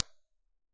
MIT_environmental_impulse_responses
Upload 16khz IR recordings
h039_Classroom_5txts.wav